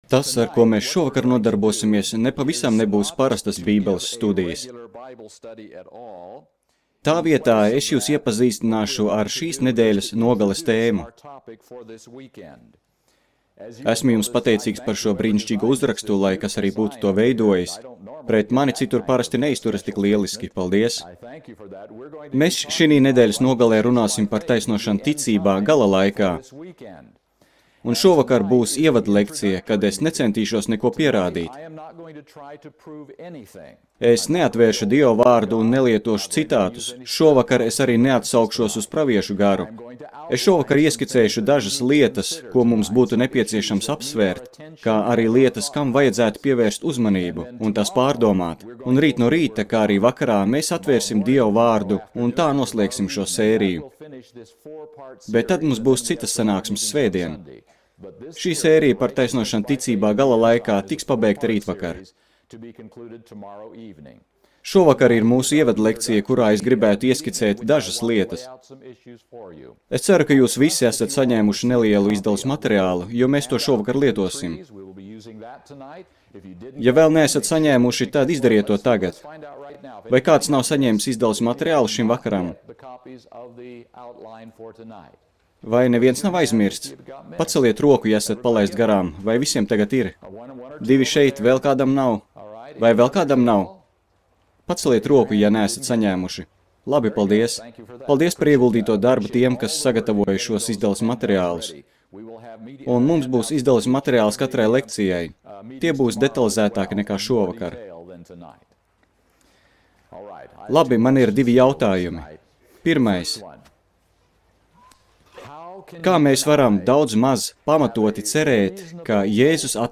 Seminārs - Taisnošana ticībā gala laikā